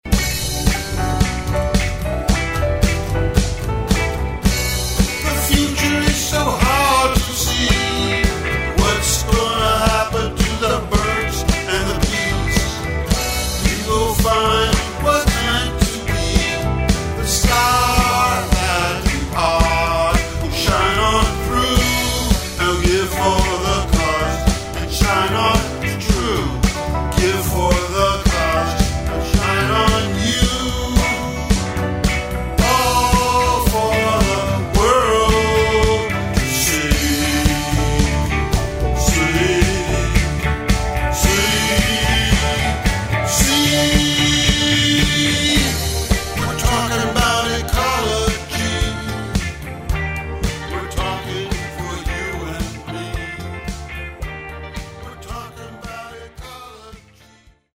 Genre: Rock & Roll.